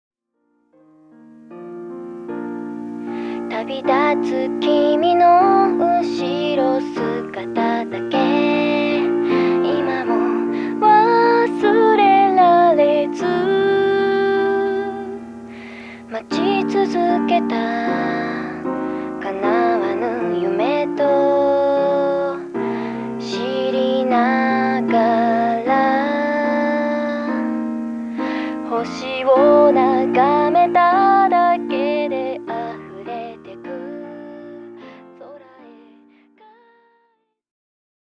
Violin
Guitars